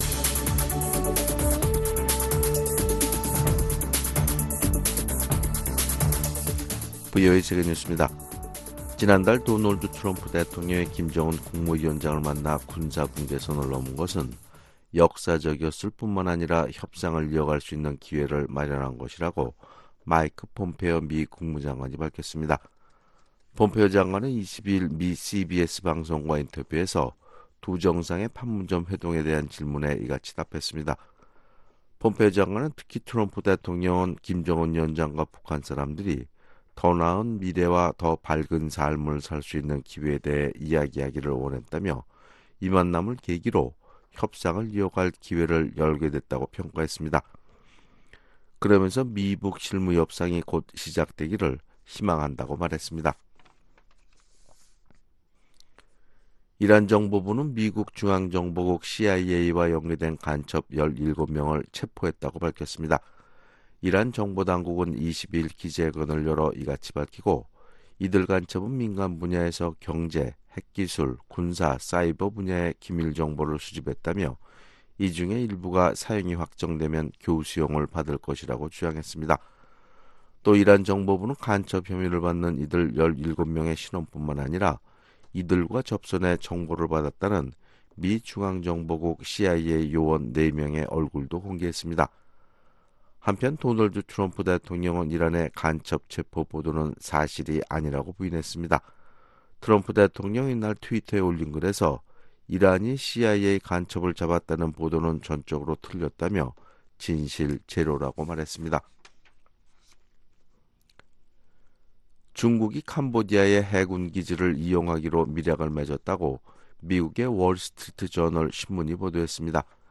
VOA 한국어 아침 뉴스 프로그램 '워싱턴 뉴스 광장' 2019년 7월 23일 방송입니다. 한국 군 당국은 다음달 예정된 미-한 훈련의 명칭에서 ‘동맹’이라는 말을 제외하는 방안을 검토하고 있는 것으로 알려졌습니다. 미국과 북한이 영변 핵 시설 폐기를 결정할 경우 체계적인 환경 복원 절차를 진행해야 한다는 전문가들의 조언이 나왔습니다.